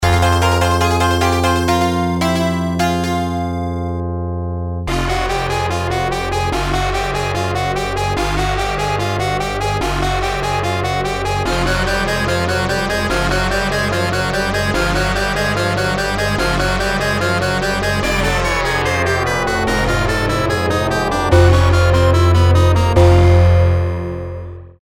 Su estilo mezcla synth-rock potente con toques clásicos.